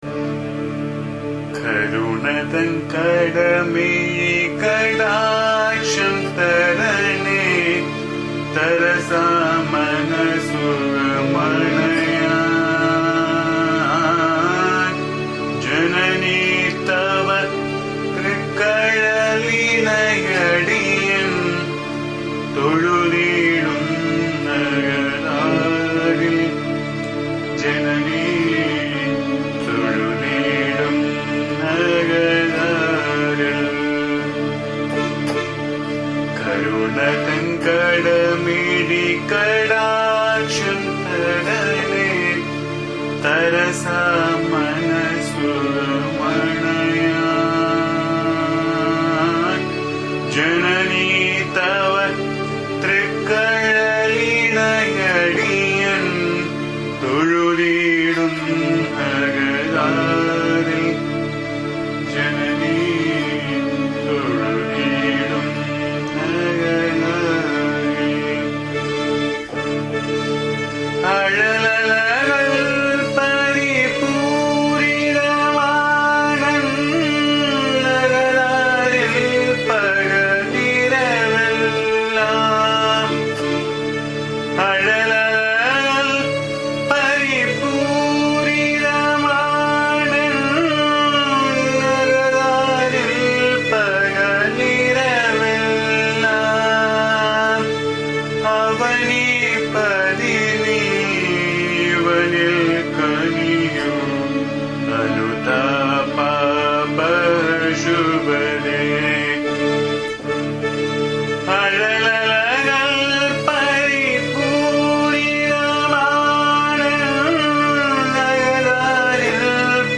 This is a very meaningful old bhajan of AMMA where the devotee cries to the divine mother to shower rays of compassion and love so that his/her mind will get filled with bliss. The song is loosely based on Kaapi Raga.